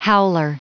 Prononciation du mot howler en anglais (fichier audio)
Prononciation du mot : howler